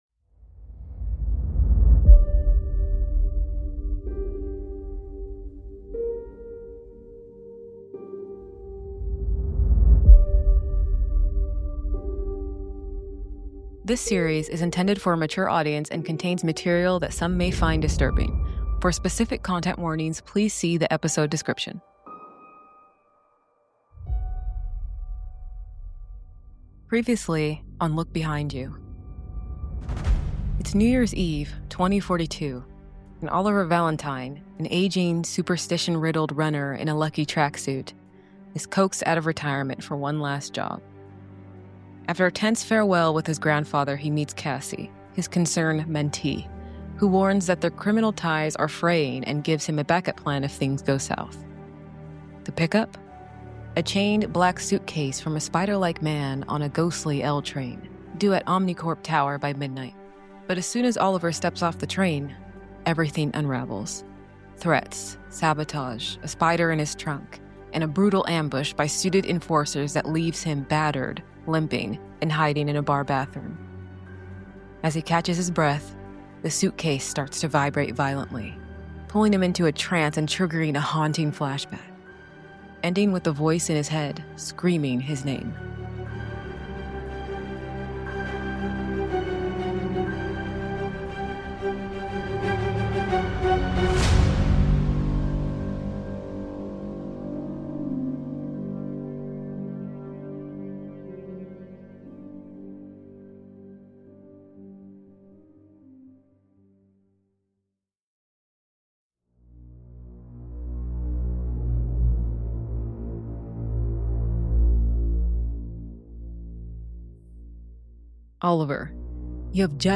Content Warning: Graphic Violence, Body Horror, Psychological Horror, Paranoia, Kidnapping & Hostage Situations, Supernatural & Cosmic Horror, Arachnophobia, Strong Language, Depictions of Pain & Injury